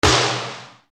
SWISSIN-SD.wav